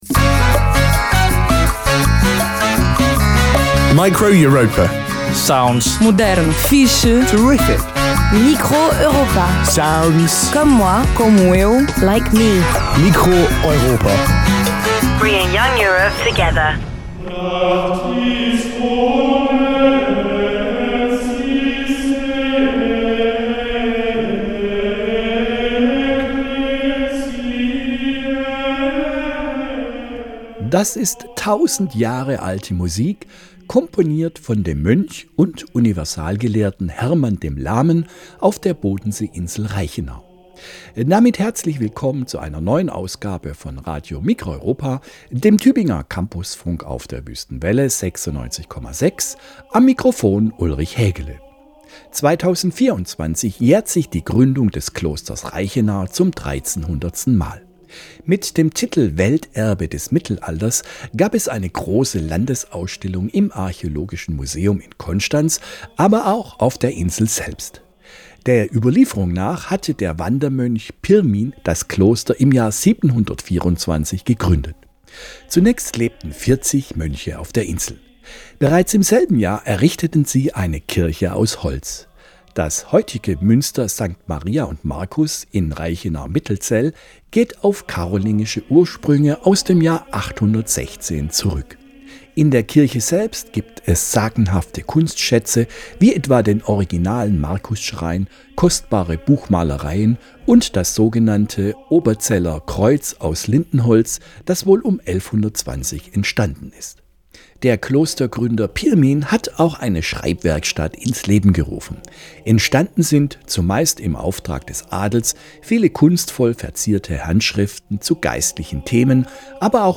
Aufgenommen wurde es im Tübinger Brechtbau, wohl im selben Studio, in dem wir aktuell unsere CampusFunk-Sendungen produzieren.
Form: Live-Aufzeichnung, geschnitten